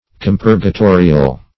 Search Result for " compurgatorial" : The Collaborative International Dictionary of English v.0.48: Compurgatorial \Com*pur`ga*to"ri*al\, a. Relating to a compurgator or to compurgation.
compurgatorial.mp3